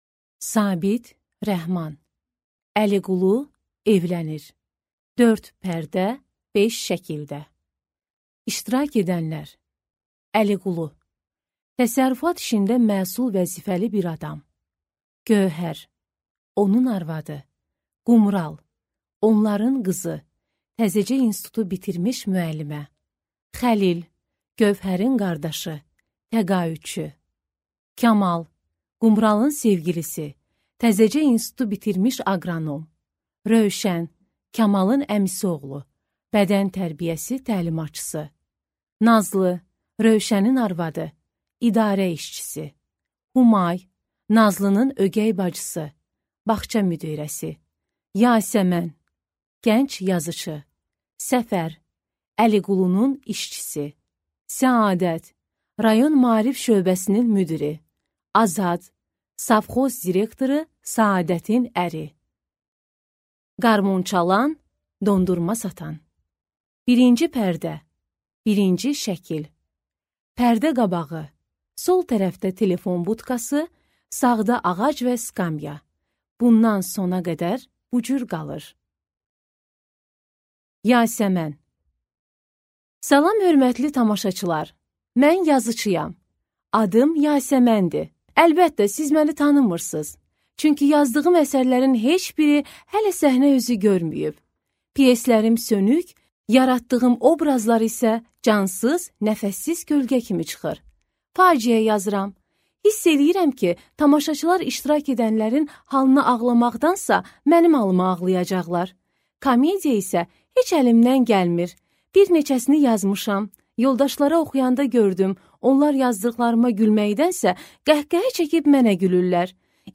Аудиокнига Əliqulu evlənir | Библиотека аудиокниг